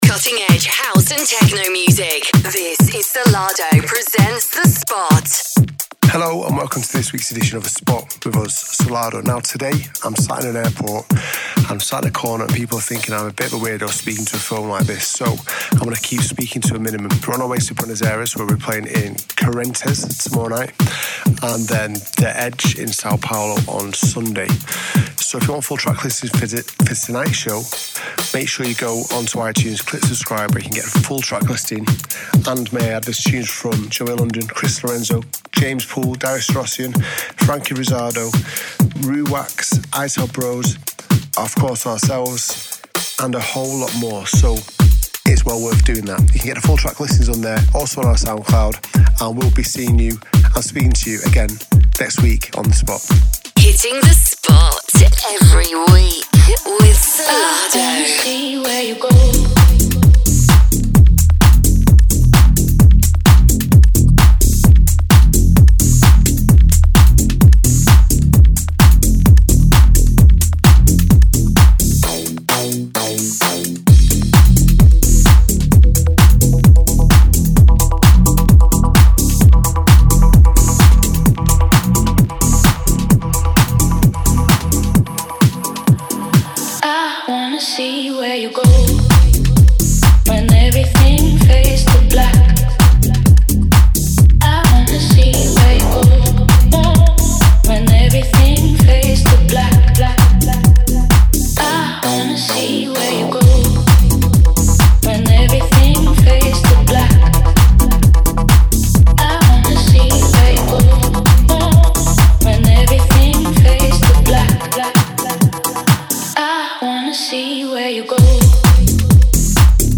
music DJ Mix in MP3 format
Genre: Tech House